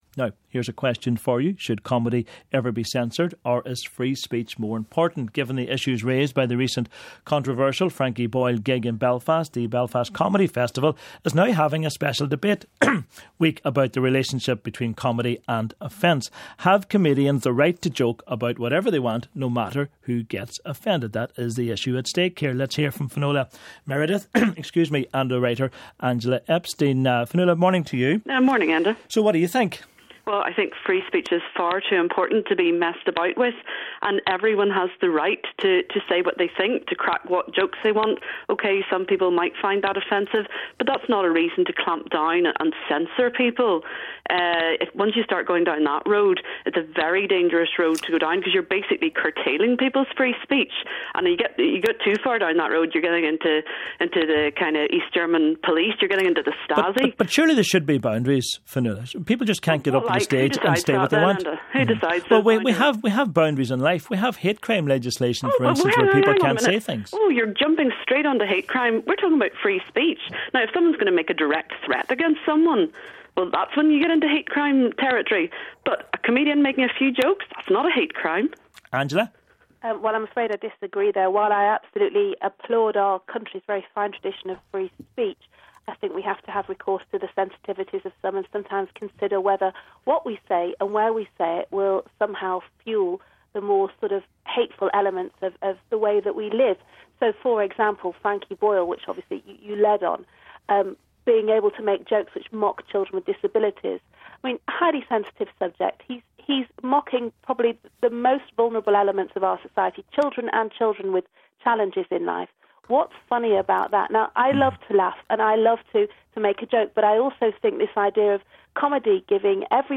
Should comedy ever be censored or is free speech more important? Given the issues raised by the recent controversial Frankie Boyle gig in Belfast, the Belfast Comedy Festival is having a special debate week about the relationship between comedy and offence. Have comedians the right to joke about whatever they want, no matter who gets offended?